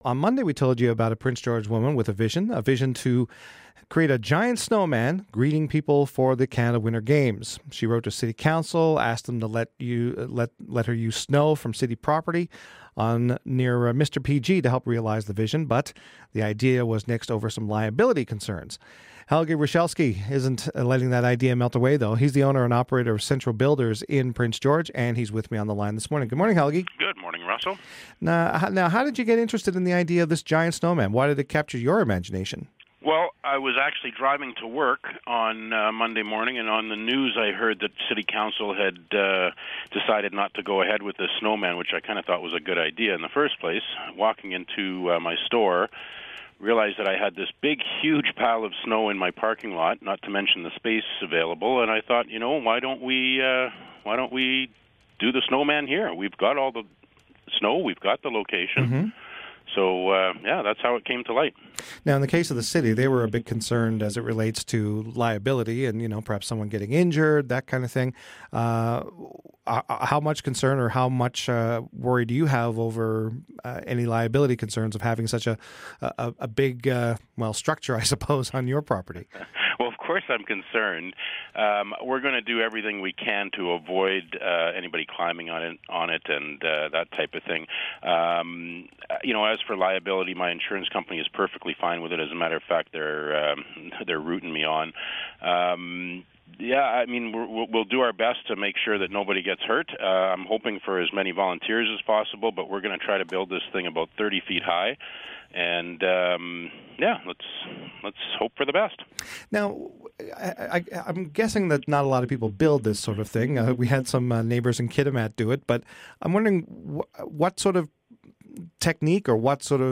Listen to our interview with the Prince George business owner who's offering up his own property --and snow-- to help build a giant snowman for the Canada Winter Games.